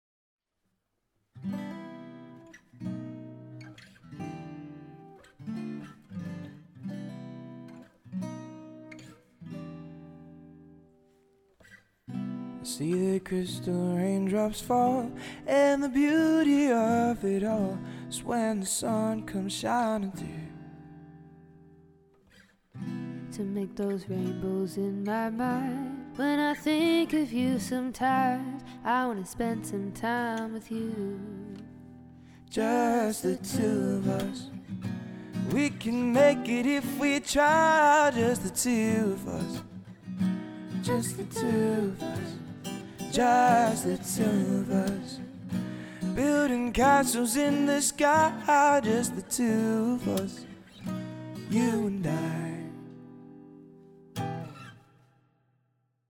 Dual Vocals | Guitar | Looping